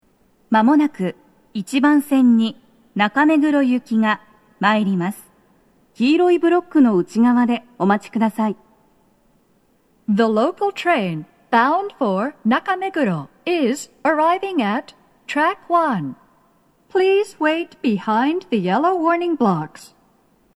–Â“®‚ÍA‚â‚â’x‚ß‚Å‚·B